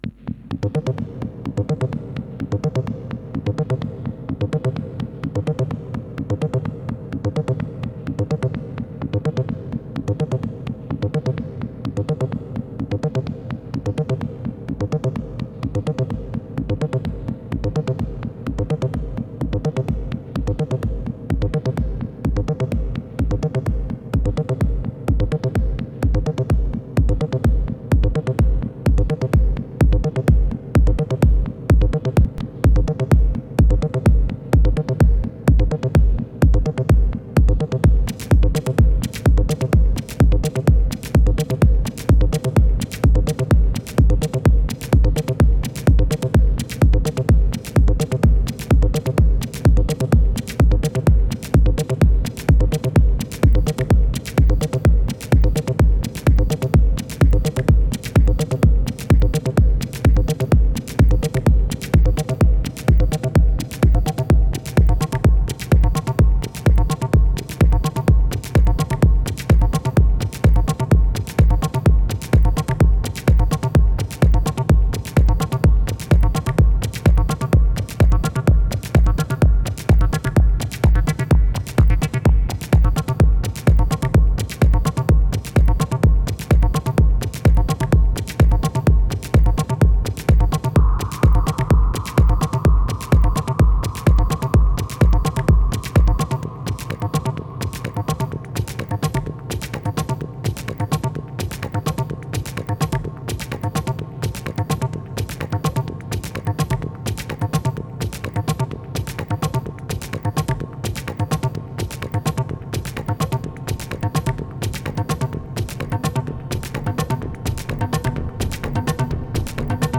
"just for fun session/ einfach spass am rumschrauben...
in this session: mfb microzwerg kraftzwerg megazwerg nanozwerg dark energy dark time..."